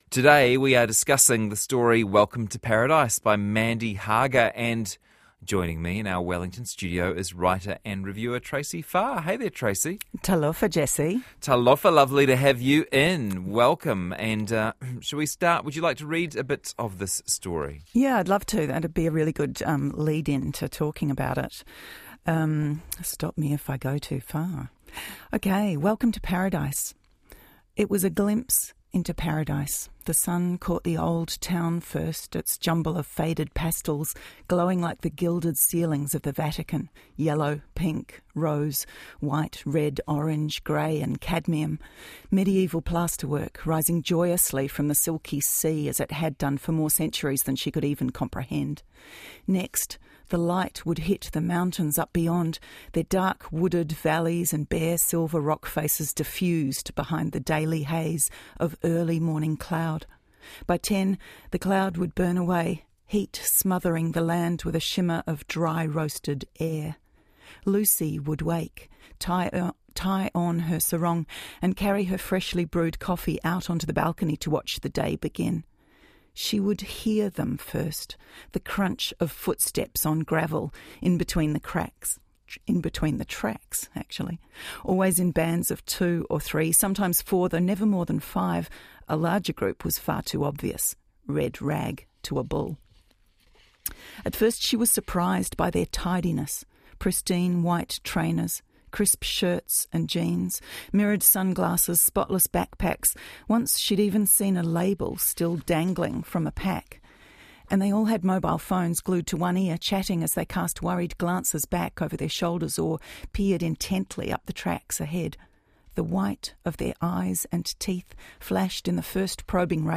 Here’s a discussion on the story